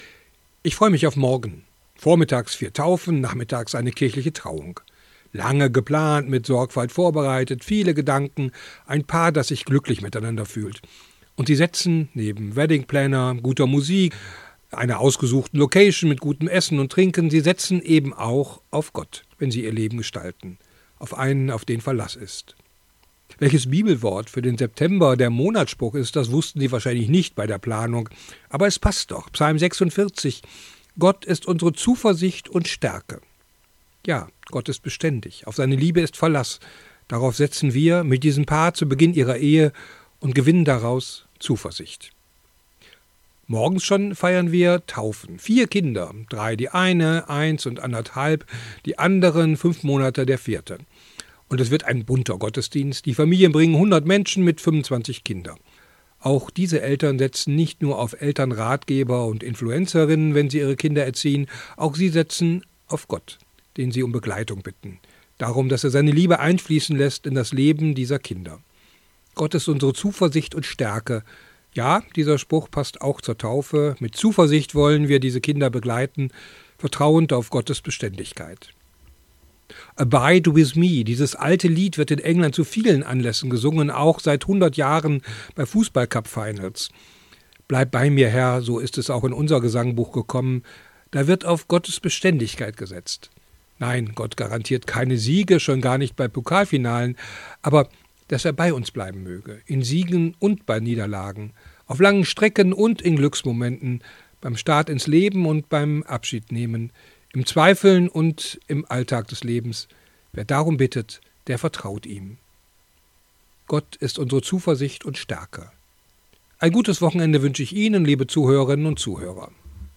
Radioandacht vom 5. September